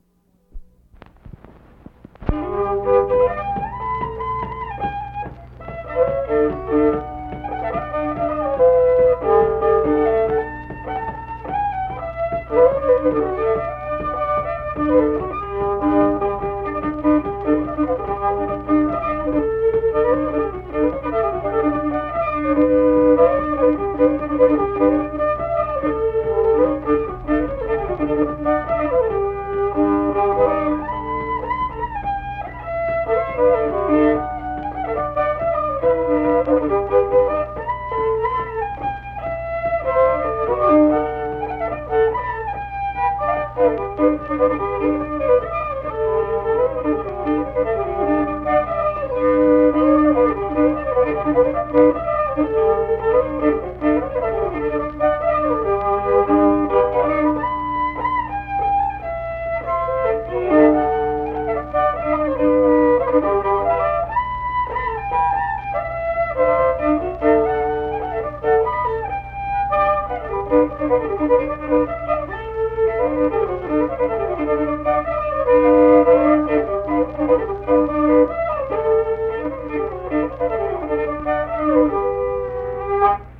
(Fiddle Tune)
Unaccompanied vocal and fiddle music
Instrumental Music
Voice (sung), Fiddle
Mingo County (W. Va.), Kirk (W. Va.)